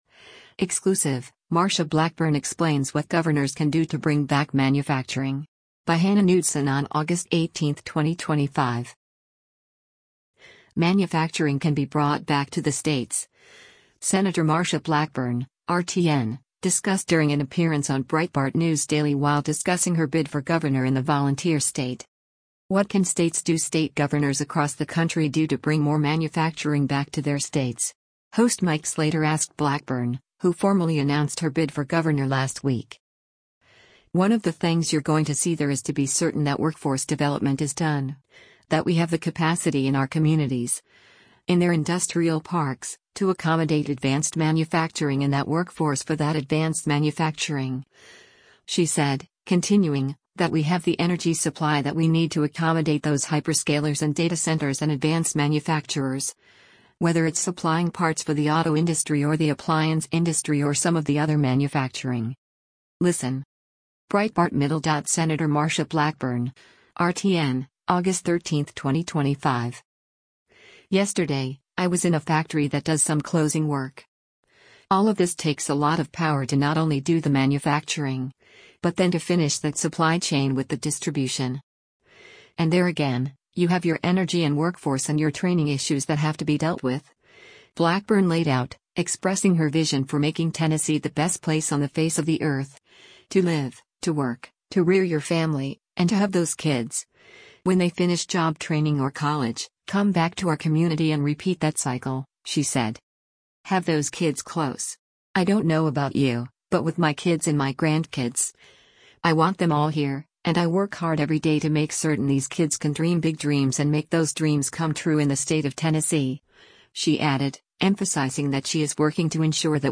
Manufacturing can be brought back to the states, Sen. Marsha Blackburn (R-TN) discussed during an appearance on Breitbart News Daily while discussing her bid for governor in the Volunteer State
Breitbart News Daily airs on SiriusXM Patriot 125 from 6:00 a.m. to 9:00 a.m. Eastern.